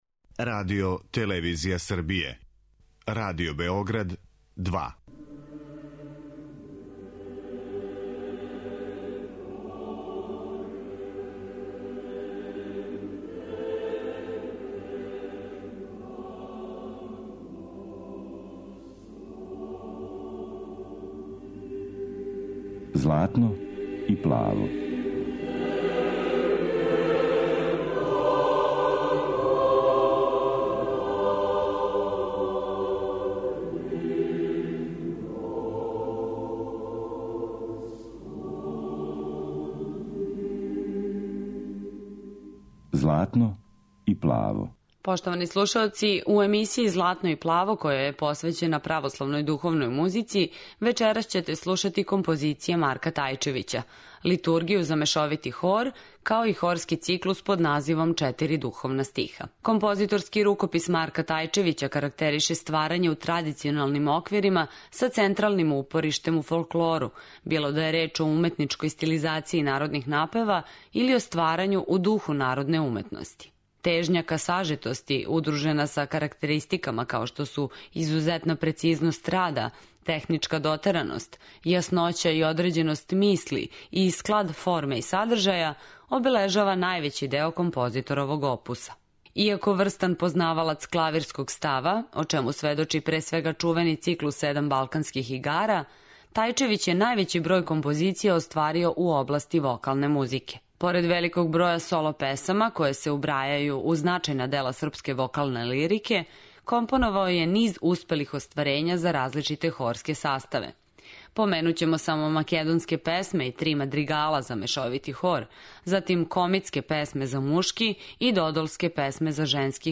Слушаћете Литургију Св. Јована Златоустог и хорски циклус Четири духовна стиха.